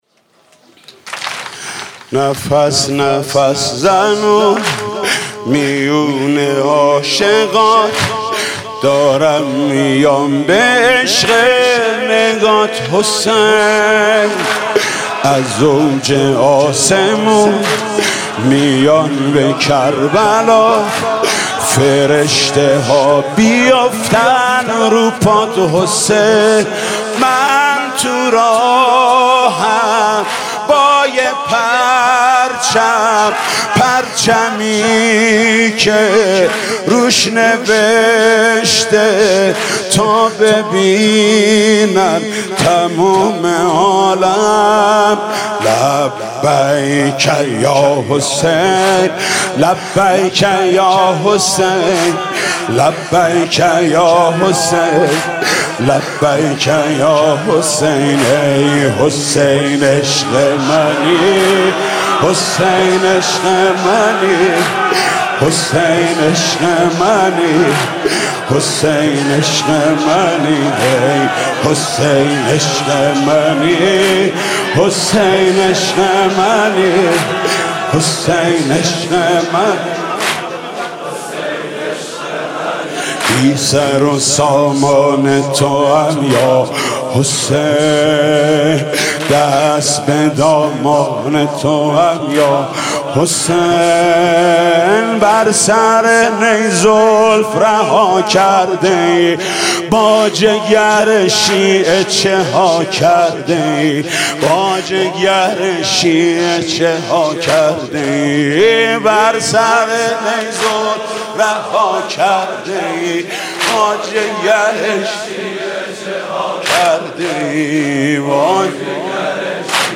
«مداحی ویژه اربعین 1394» واحد: نفس نفس زنون میون عاشقات